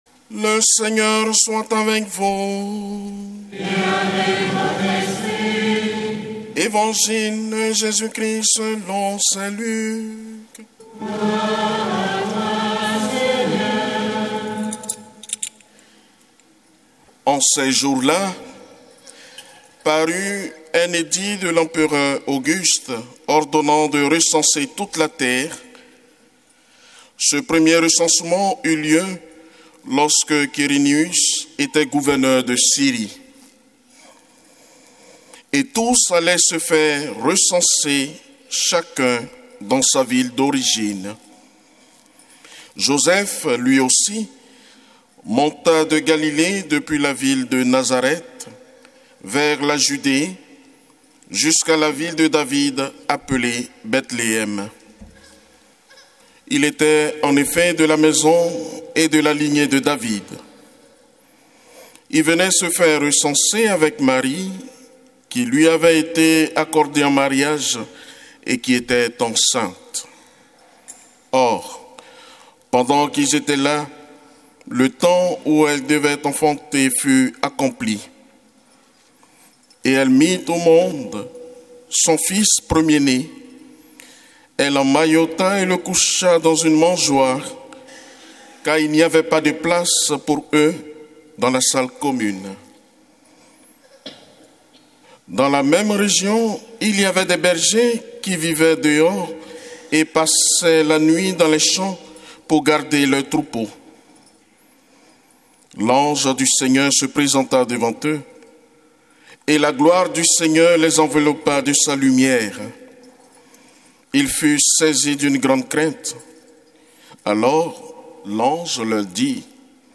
Paroisse St Charles de Foucauld | Noël 2024 — Messe de la nuit — Année C
Évangile de Jésus Christ selon saint Luc avec l'homélie